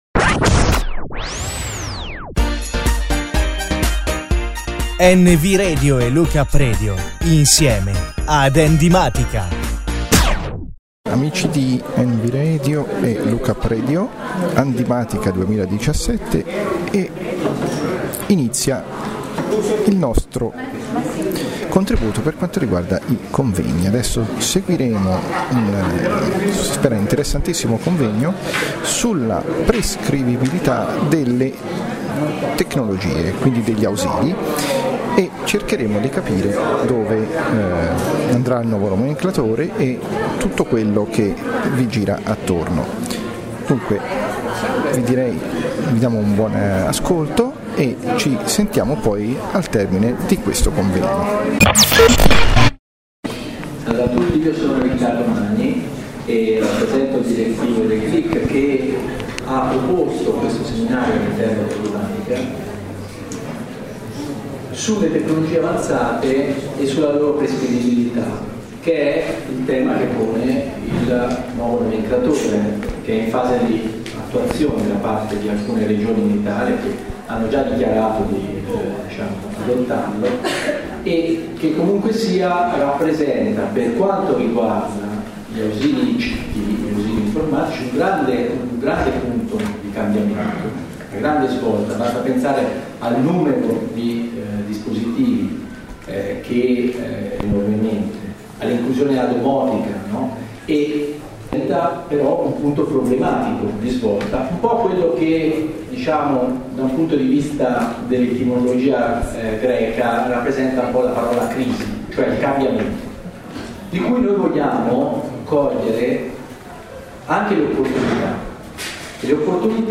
Registrazione del convegno sul nomenclatore tariffario, parte prima.